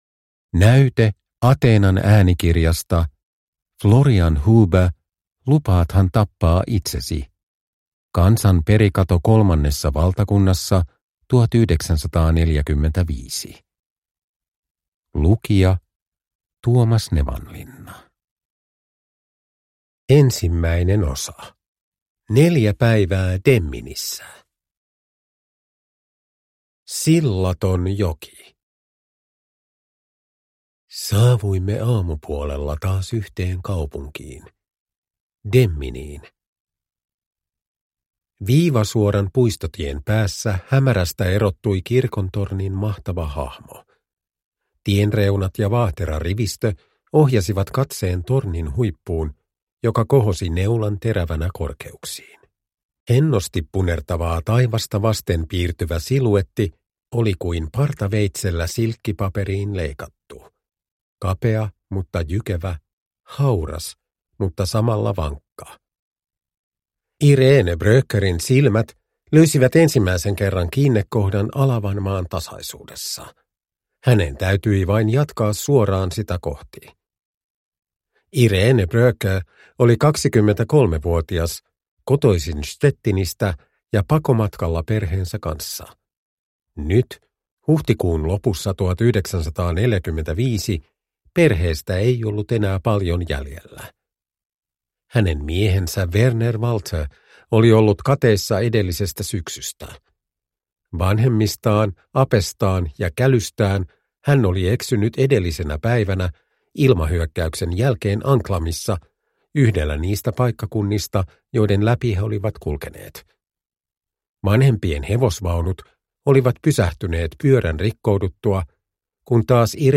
Uppläsare: Tuomas Nevanlinna